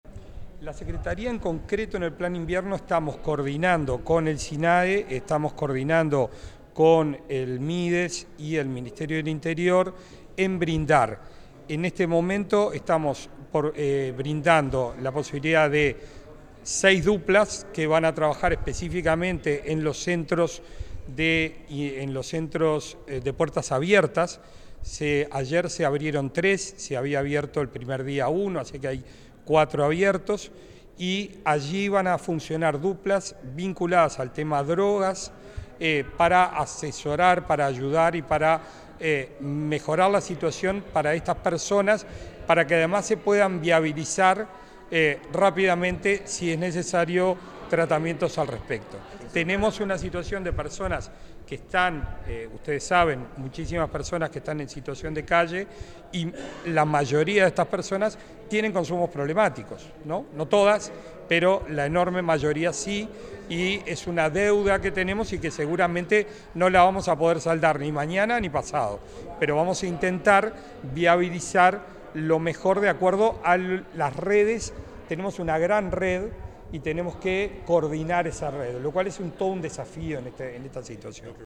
Declaraciones del titular de la Secretaría Nacional de Drogas, Gabriel Rossi 26/06/2025 Compartir Facebook X Copiar enlace WhatsApp LinkedIn El titular de la Secretaría Nacional de Drogas, Gabriel Rossi, realizó declaraciones en la Torre Ejecutiva, referidas al rol de la Junta Nacional de Drogas en el Plan Invierno.